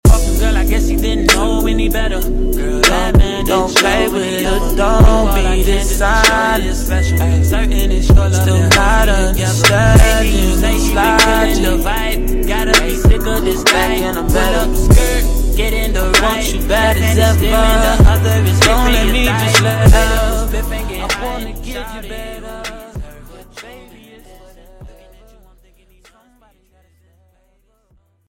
Meow Sound Effects Free Download